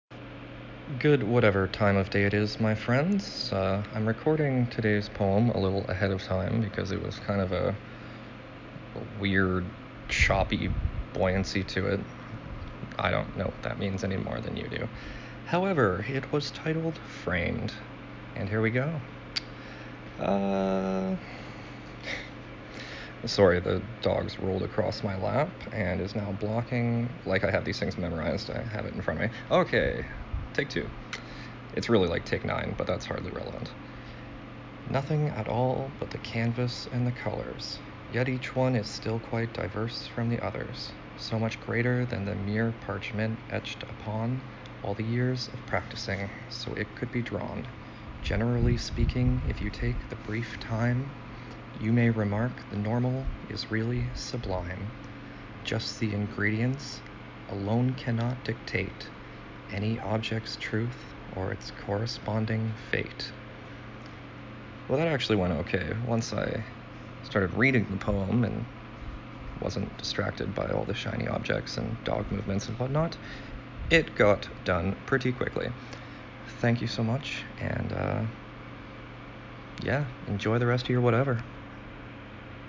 It actually took me a few times during the recording process below to get the rhythm proper.
I threw in a few commas to try to help denote the proper rhythm.